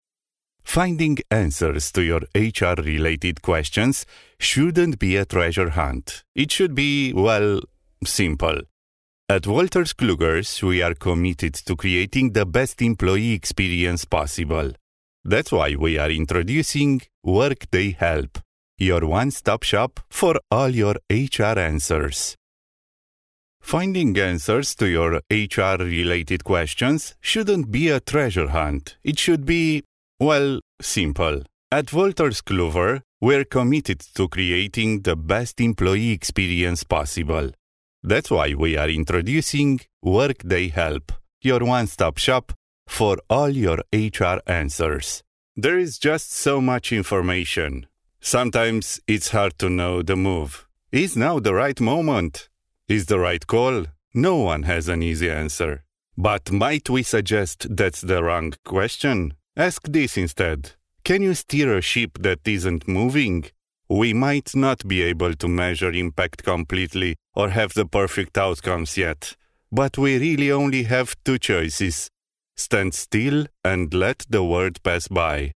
Experienced Romanian male, native, voice talent
English with Romanian accent